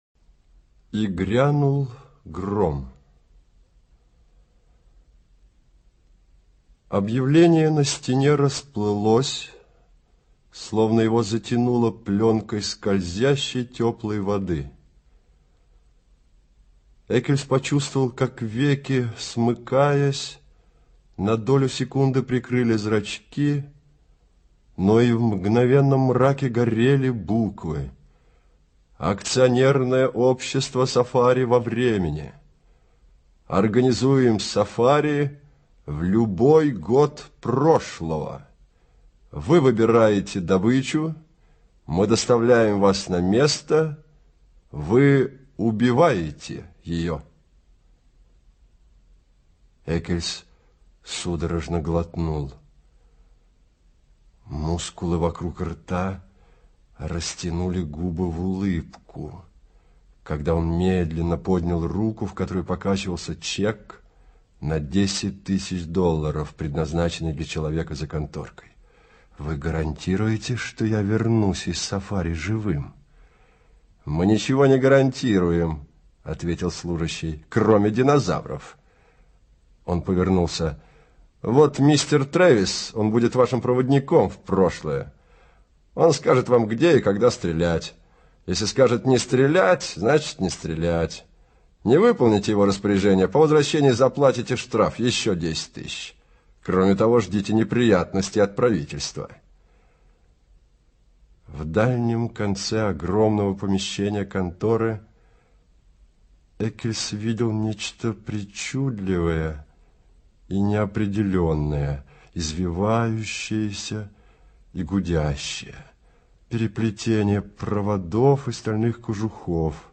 И грянул гром - аудио рассказ Брэдбери Р. Рассказ об ответственности каждого человека за свои поступки, совершенные в жизни.